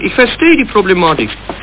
( .mp3 ) < prev next > Amiga 8-bit Sampled Voice | 1997-06-12 | 14KB | 1 channel | 8,363 sample rate | 1 second
ich-verstehe_die....mp3